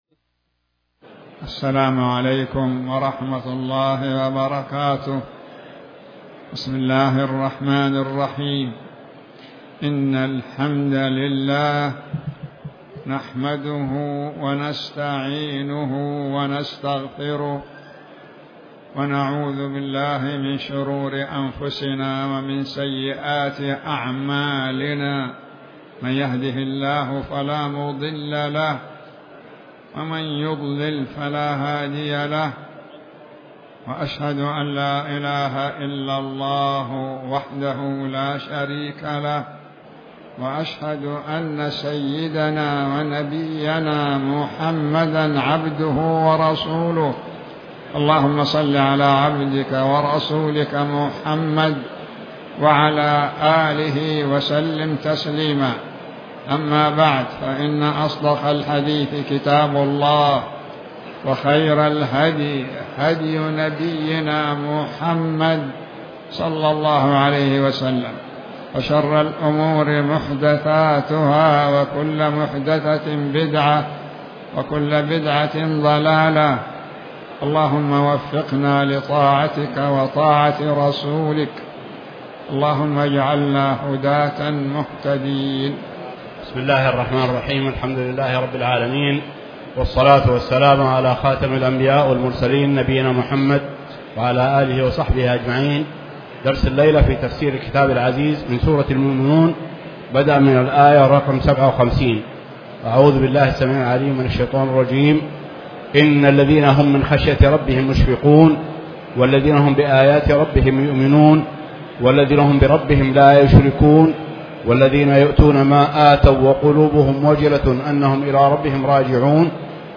تاريخ النشر ٧ محرم ١٤٤٠ هـ المكان: المسجد الحرام الشيخ